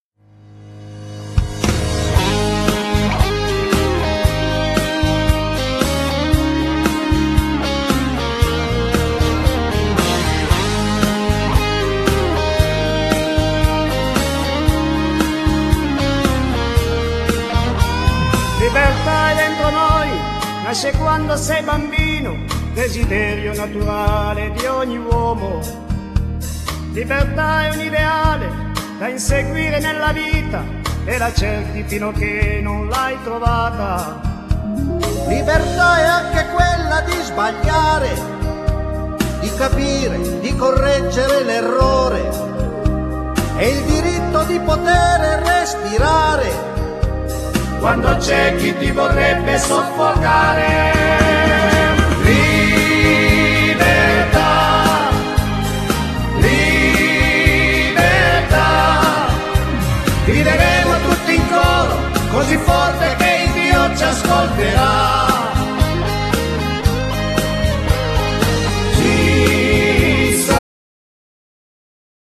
voce da I° tenore-contralto con spiccate note in soprano.
Genere : Liscio folk